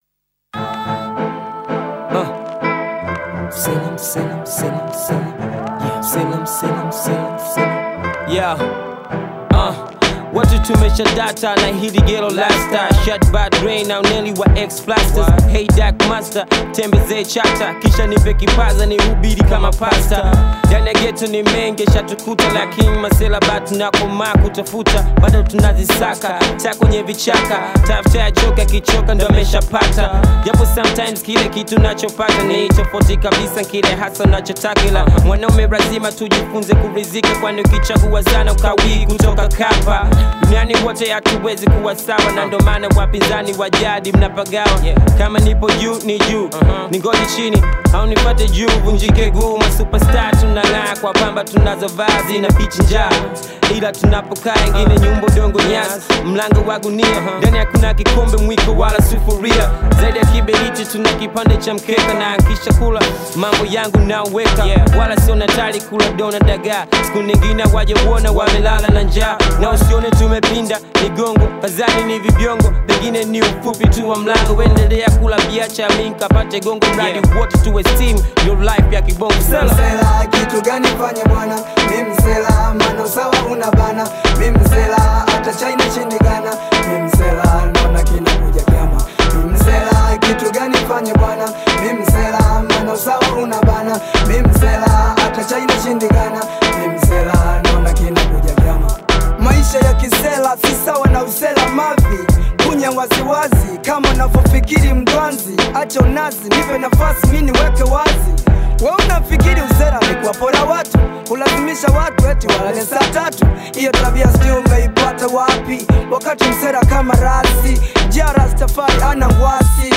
In the vibrant tapestry of Tanzanian music